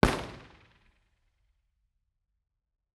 IR_EigenmikeHHR1_processed_Bformat.wav